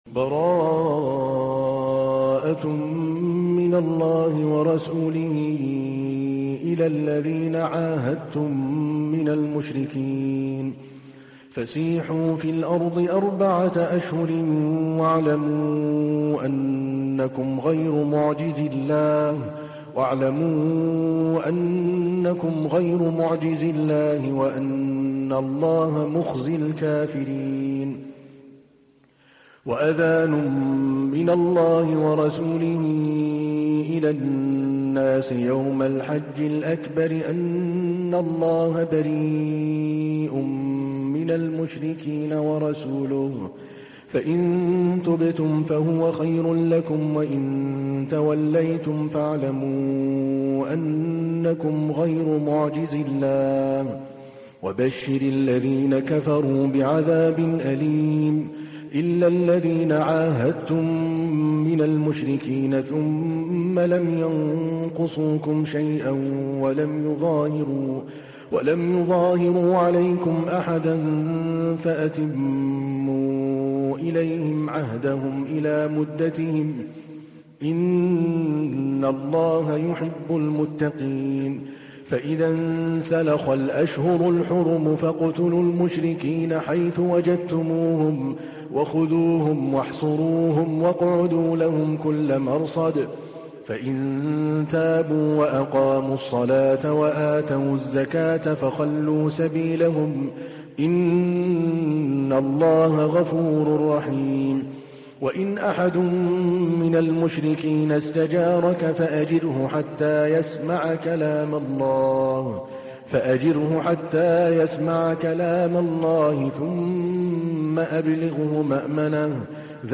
تحميل : 9. سورة التوبة / القارئ عادل الكلباني / القرآن الكريم / موقع يا حسين